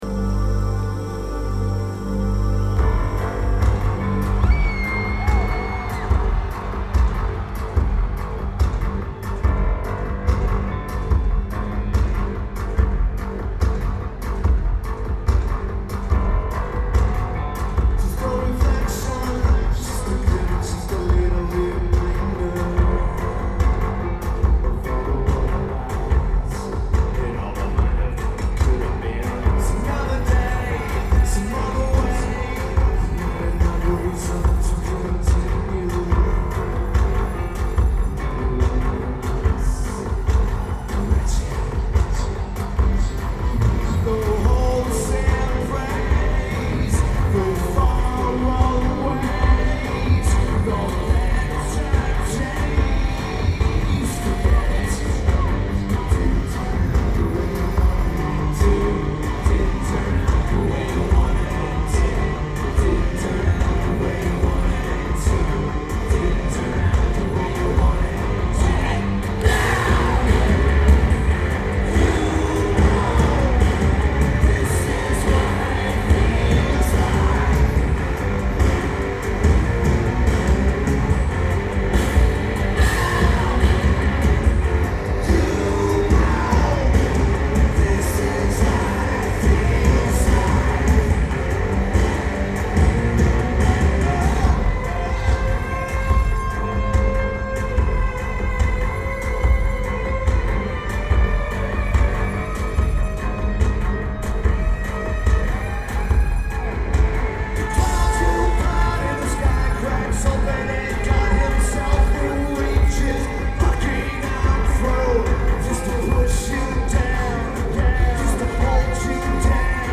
Lineage: Audio - AUD (AT853 + SP-BB + Sony TCD-D8)